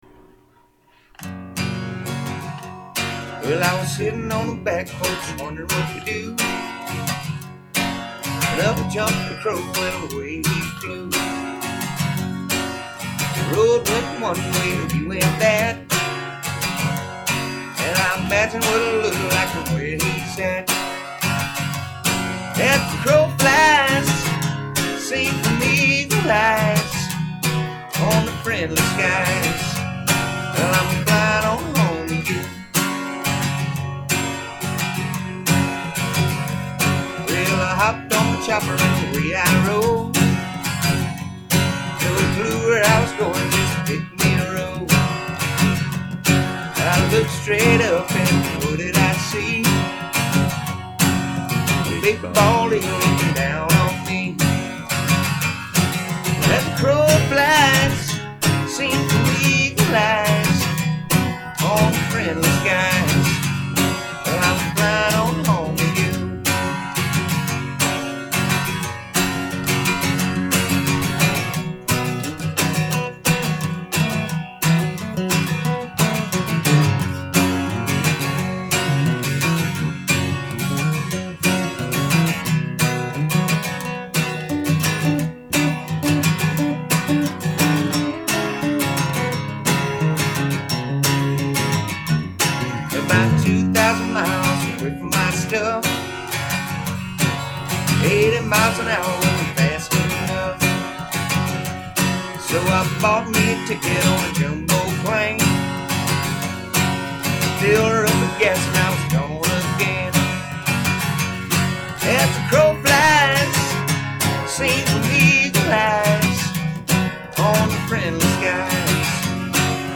Country-rock